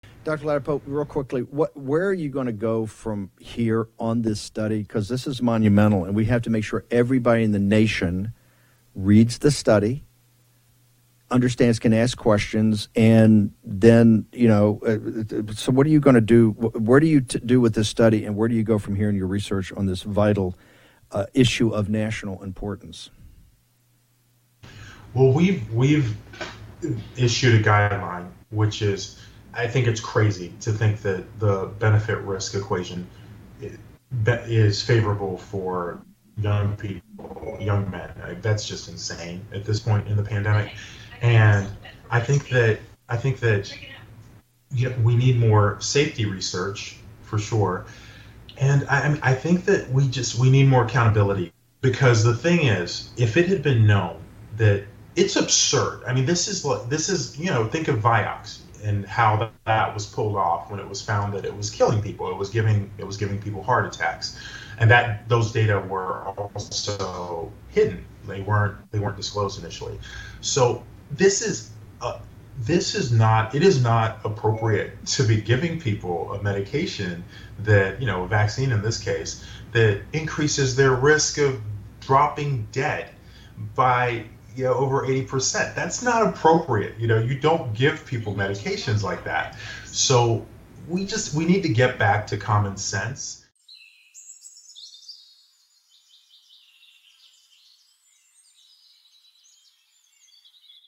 Steve Bannon interviews Dr. Joseph Ladapo, Florida's Surgeon General.